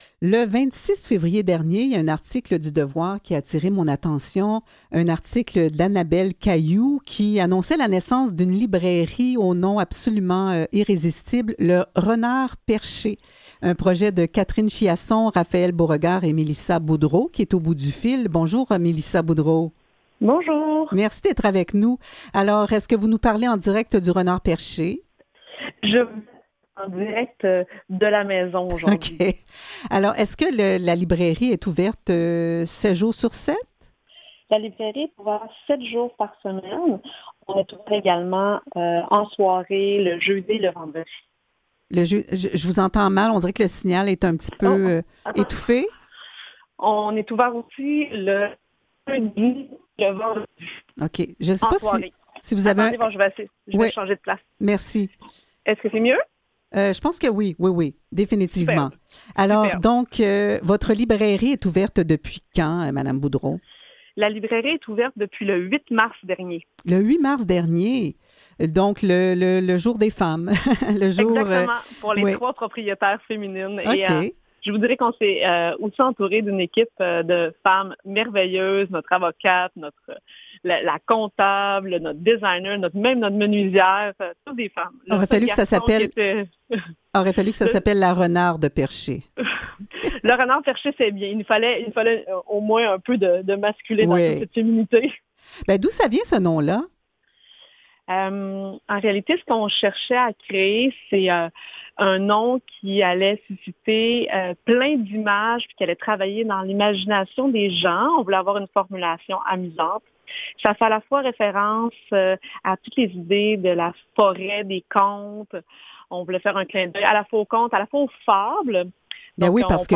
Aux Quotidiens Revue de presse et entrevues du 19 juillet 2021